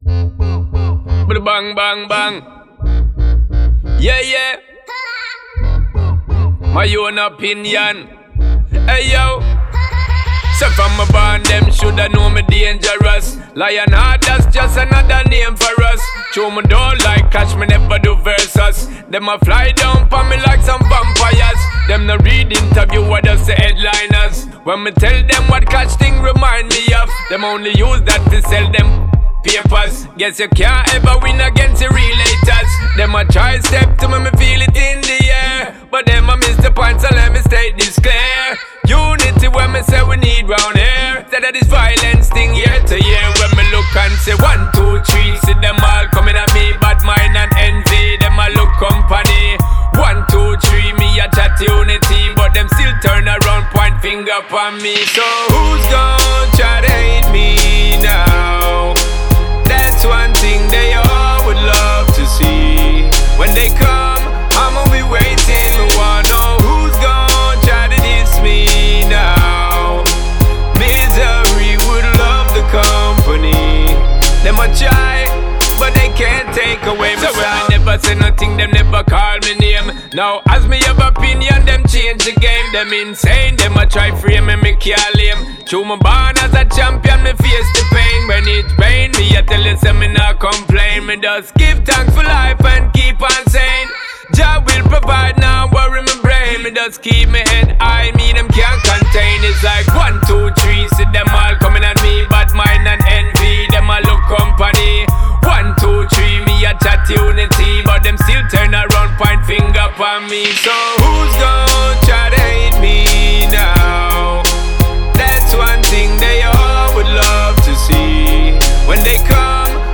это энергичная композиция в жанре регги и дэнсхолл